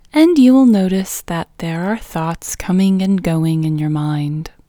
QUIETNESS Female English 4
Quietness-Female-4-1.mp3